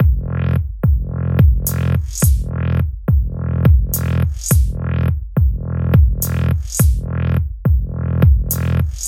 杜比斯特风格的低音踢延迟与小嗨帽
它就像低音踢球上的一个奇怪的上升延迟，但对于一个低音踢球来说，它是有一定的调性的。我加入了dubstep，但只是因为它的声音让我想起了它。
标签： 105 bpm Dubstep Loops Bass Loops 1.53 MB wav Key : Unknown
声道立体声